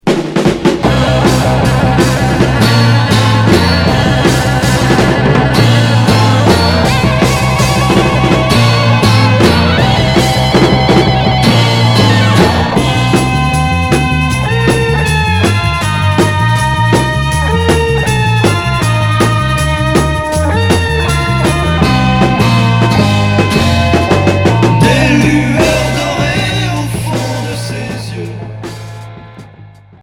Heavy rock